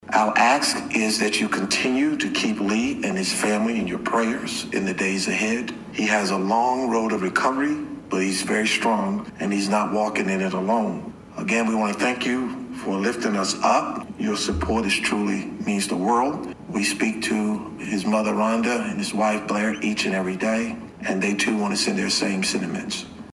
Danville City Council held its first meeting on Tuesday night since the attack on Vogler.
Mayor Jones also asked that the community continue to support Vogler and his family.